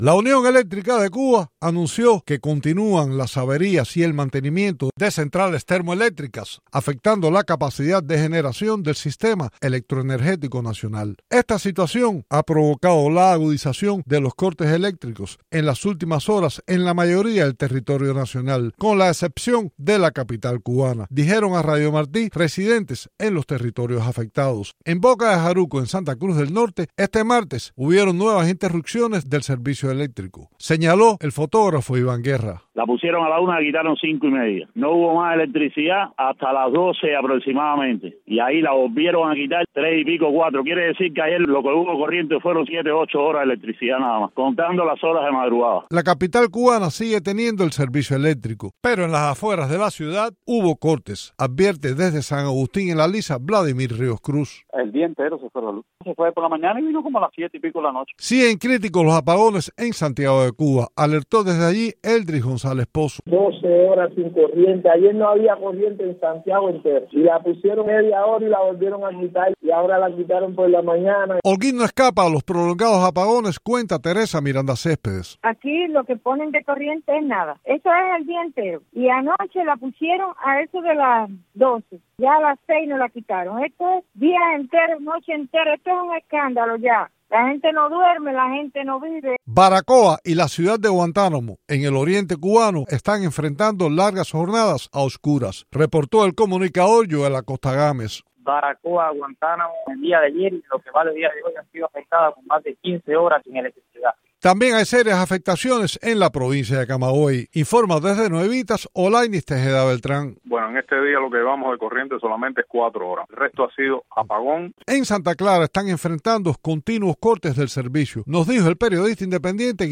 Cubanos describen su desesperación por los prolongados apagones